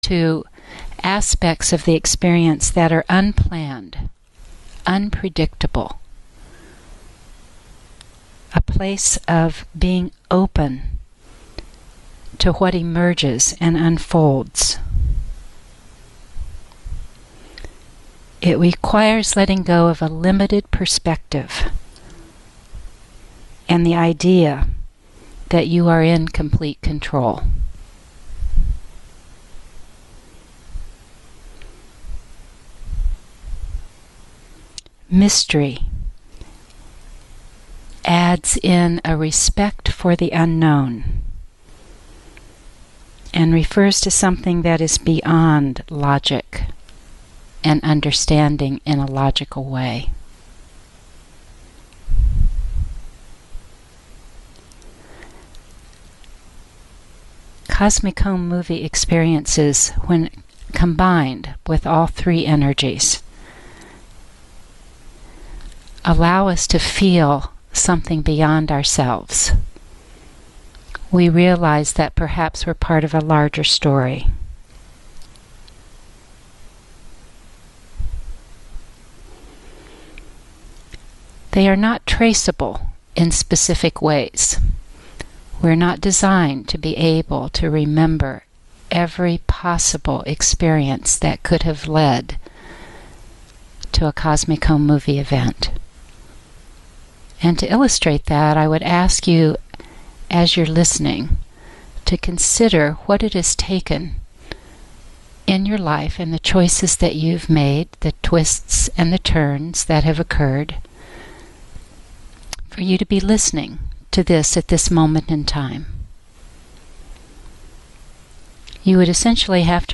Bonus – Each CAST NOTE Scene is accompanied by a recorded meditation / description of the Scene to assist with your contemplation.